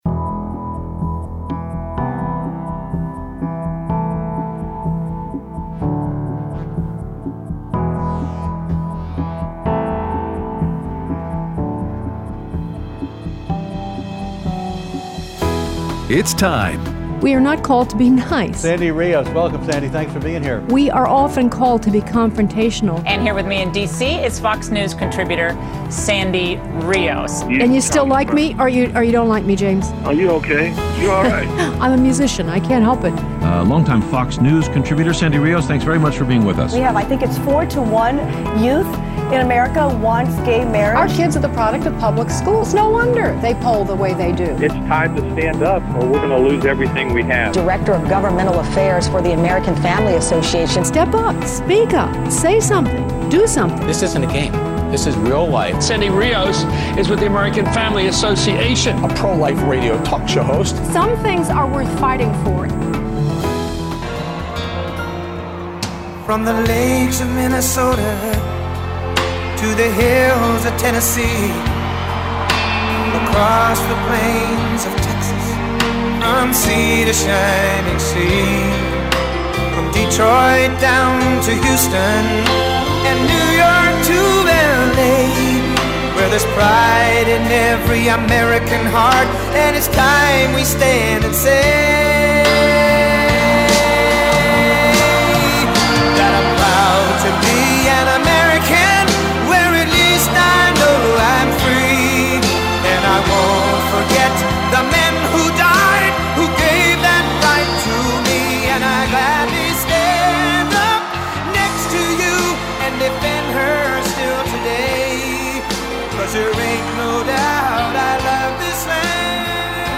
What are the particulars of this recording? Aired Wednesday 5/30/18 on AFR 7:05AM - 8:00AM CST